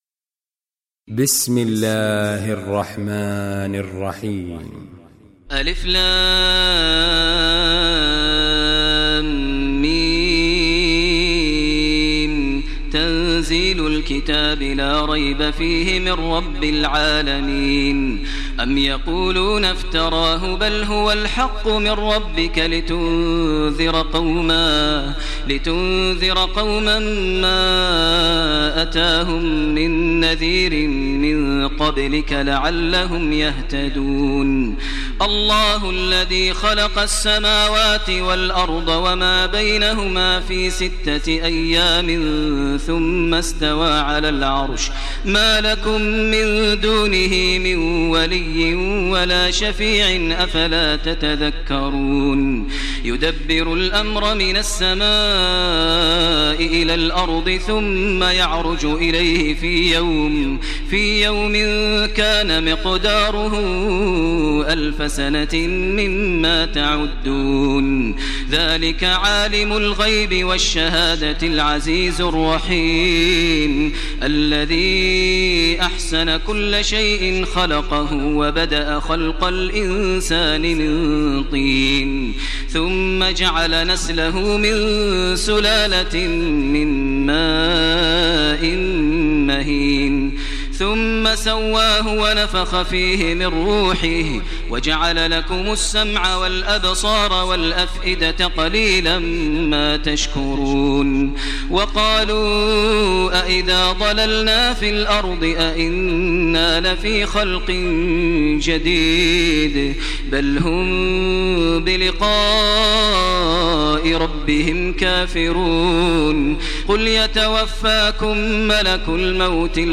Surah Sajdah Recitation by Sheikh Maher al Mueaqly
Surah Sajdah, listen online mp3 tilawat / recitation in the voice of Sheikh Maher al Mueaqly.